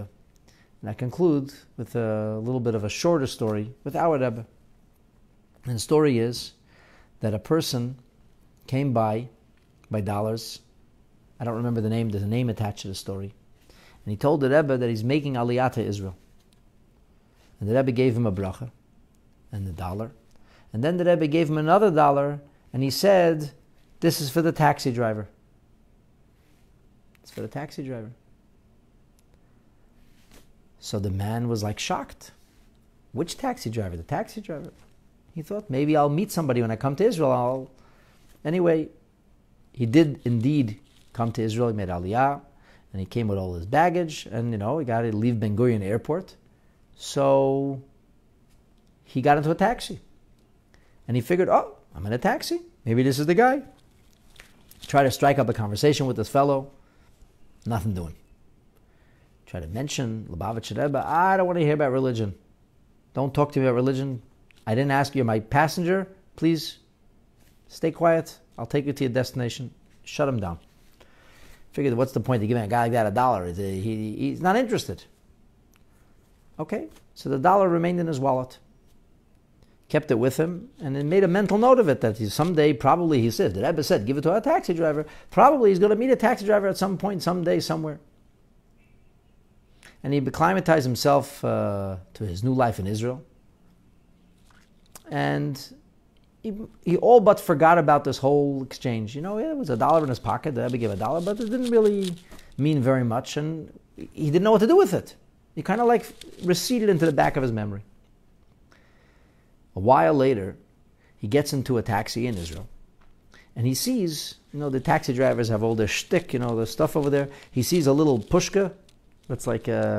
Told over at a Farbrengen